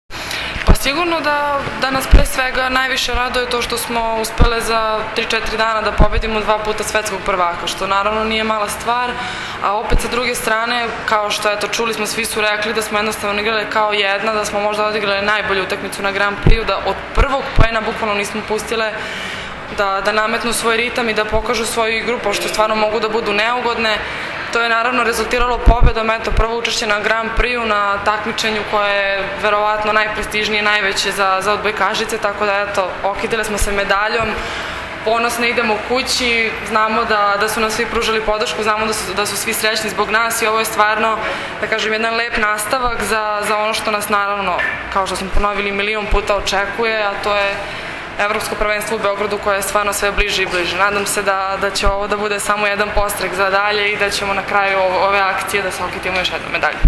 IZJAVA SANJE MALAGURSKI